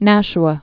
(năsh-ə)